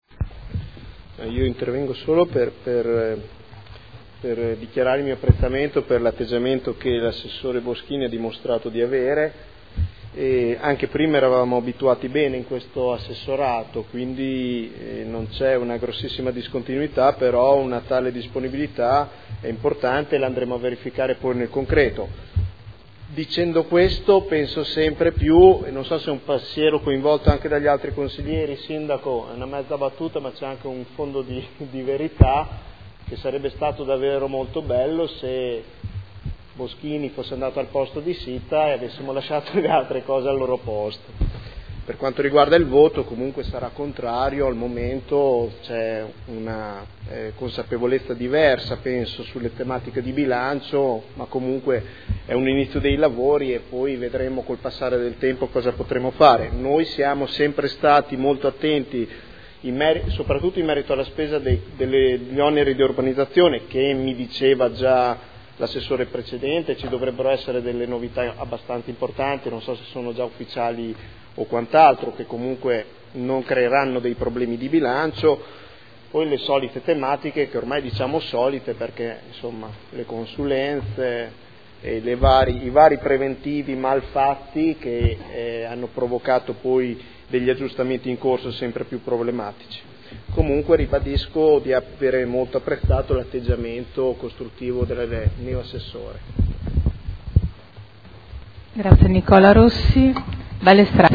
Seduta del 23/04/2012. Dichiarazione di voto su proposta di deliberazione: rendiconto della gestione del Comune di Modena per l’esercizio 2011 – Approvazione e nuovo Ordine del Giorno sempre su rendiconto esercizio 2011